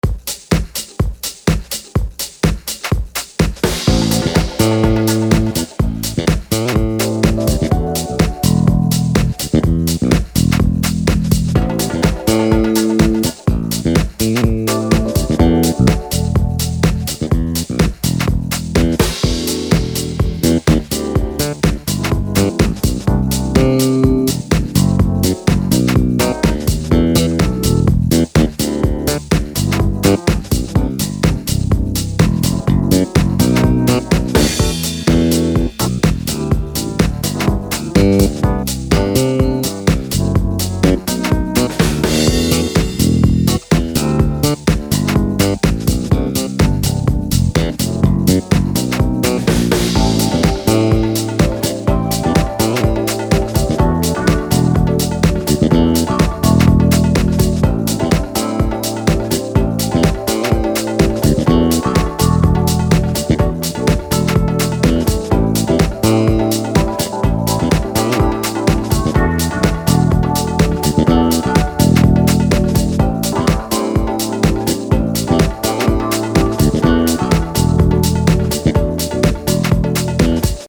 House Demo